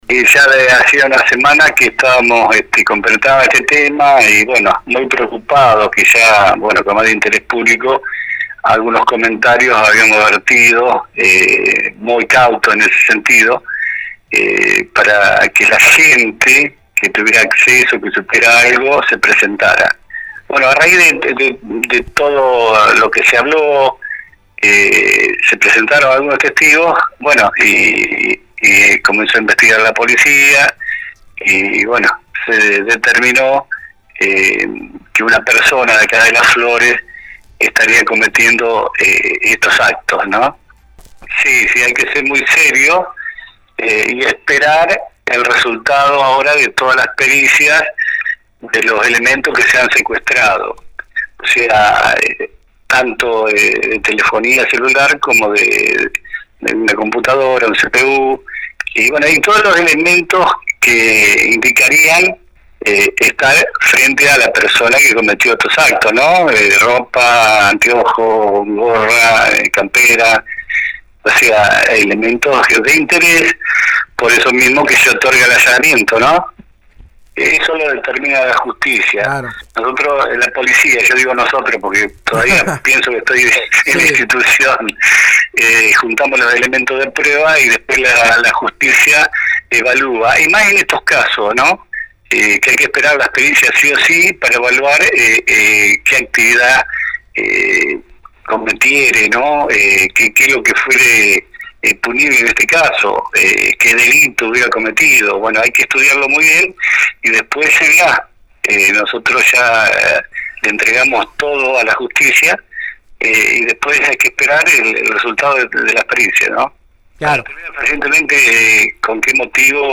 Luego del allanamiento en un domicilio de nuestra ciudad donde se secuestraron varios elementos relacionados al inquietante caso de un extraño que tomaba imágenes en las escuelas a la salida de alumnos, la 91.5 habló con el director de protección ciudadana, quien se refirió al hecho en cuestión.